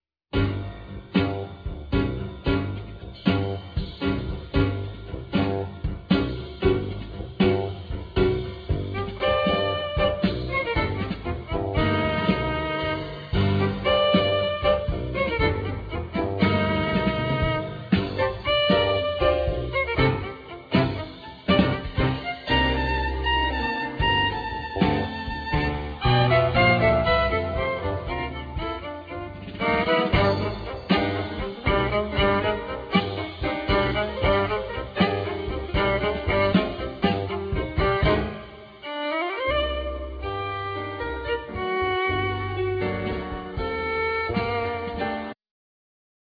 Violin
Piano
Bass
Drums